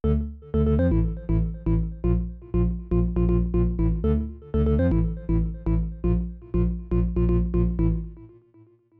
Two tracks with Sy RAW to add the missing sub :stuck_out_tongue: